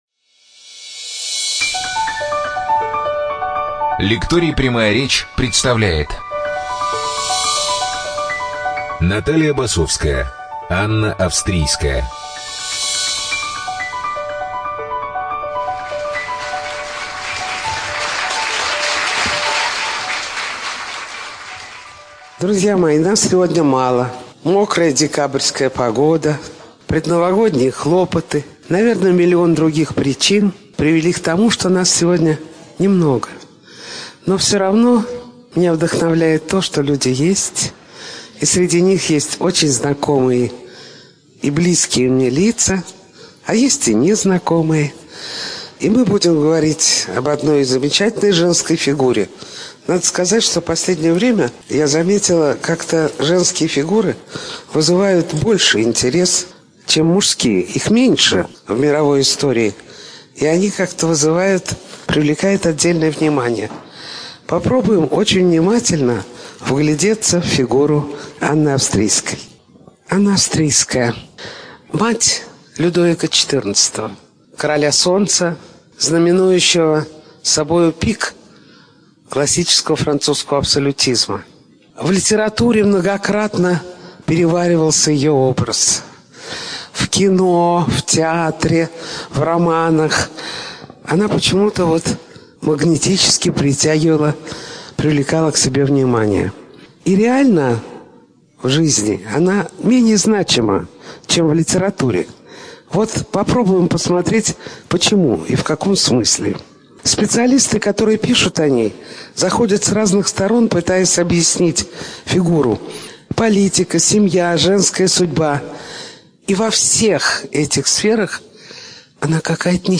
НазваниеАнна Австрийская. Лекция
ЧитаетАвтор
Студия звукозаписиЛекторий "Прямая речь"